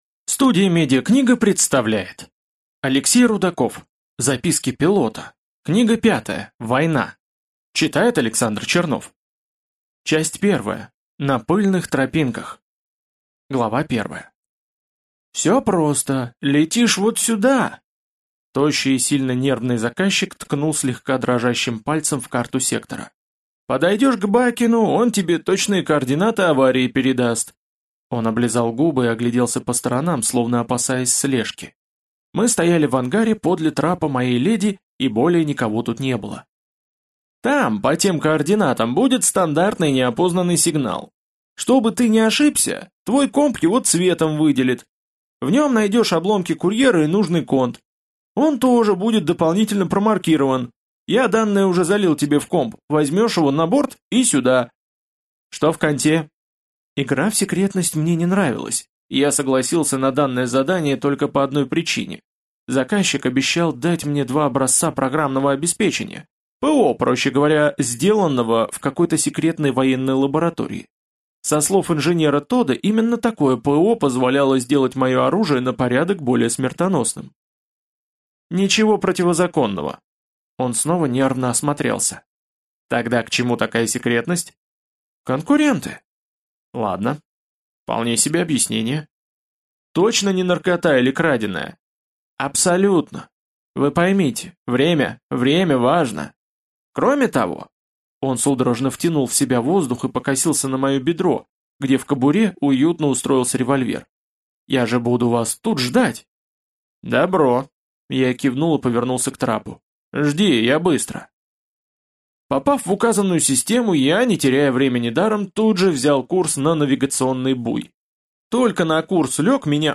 Аудиокнига Война | Библиотека аудиокниг